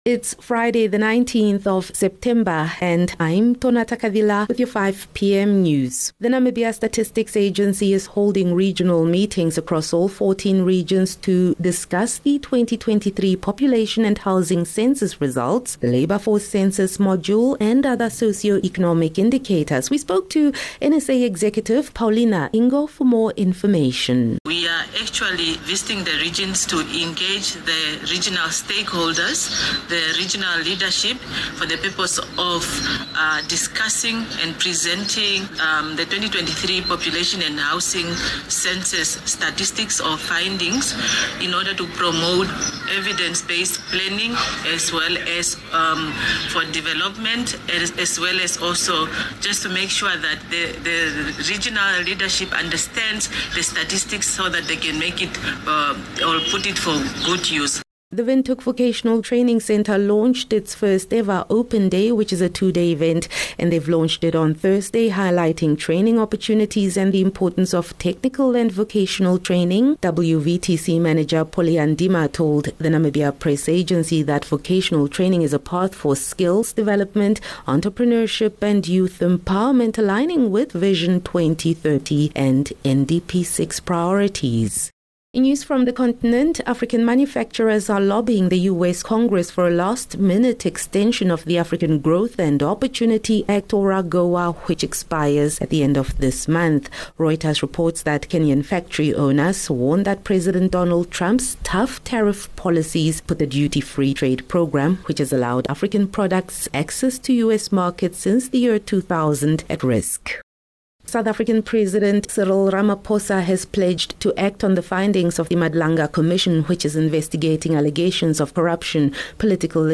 19 Sep 19 September - 5 pm news